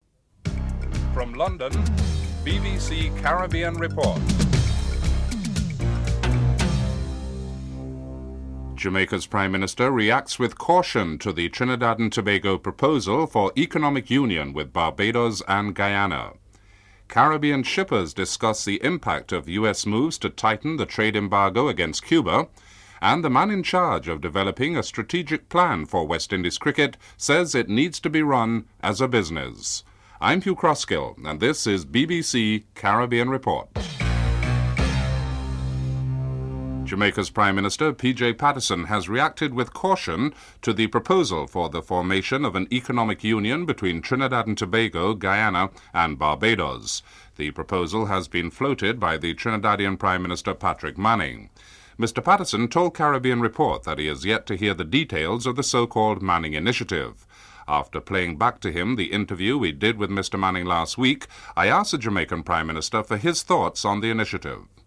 1. Headlines (00:00-:38)
6. Jamaica’s Finance Minister Hugh Small delivers budget presentation.